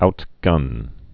(outgŭn)